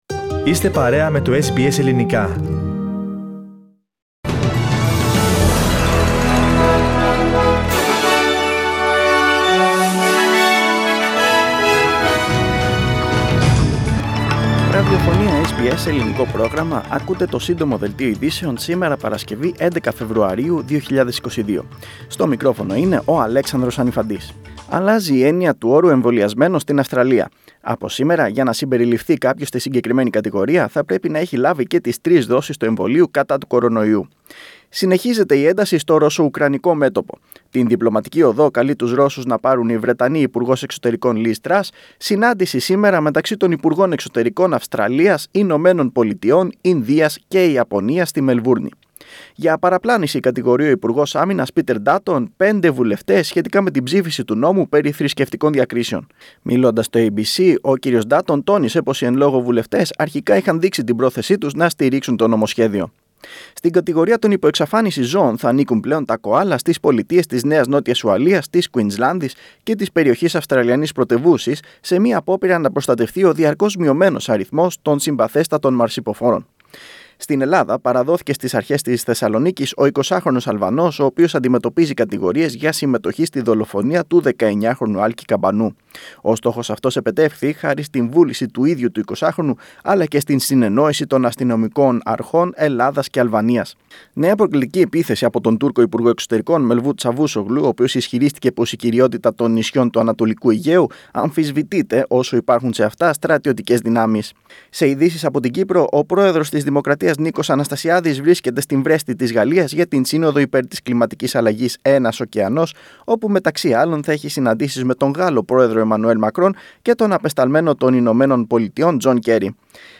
News Flash - Σύντομο Δελτίο